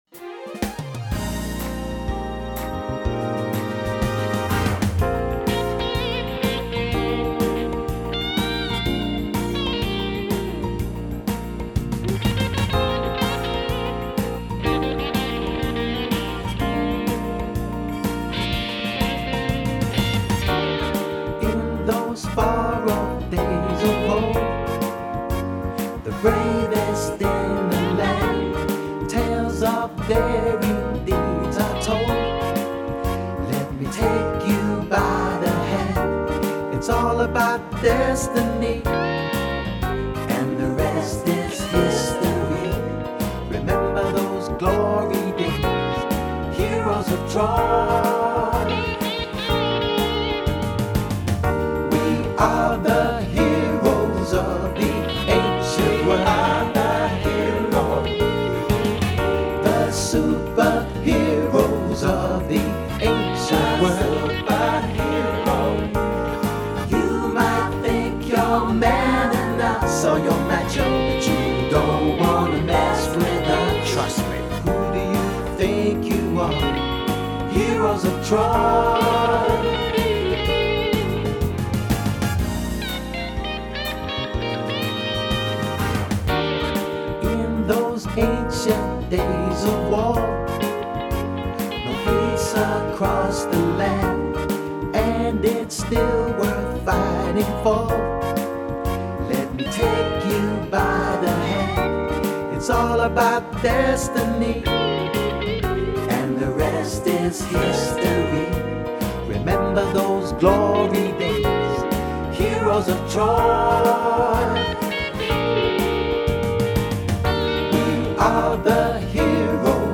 Song style: soul / swing
Sung by: The Greek winning side and chorus